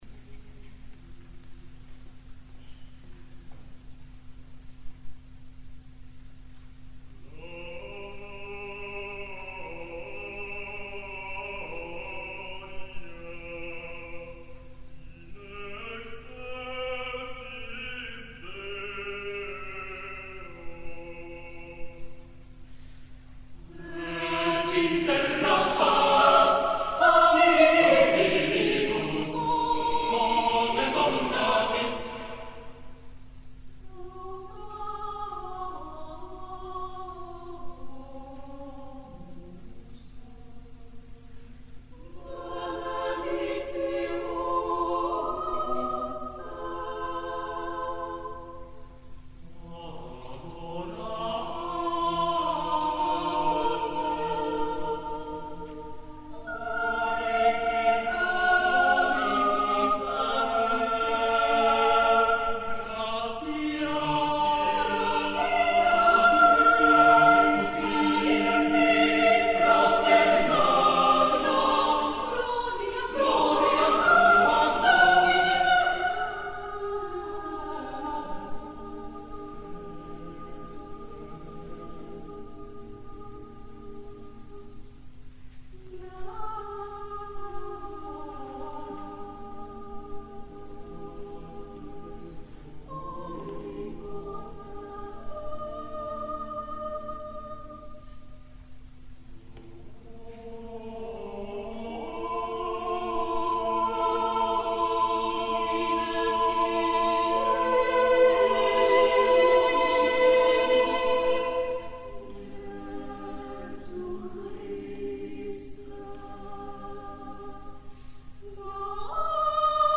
· Contenuto in Choir or Solo Voices + Instrumental ensemble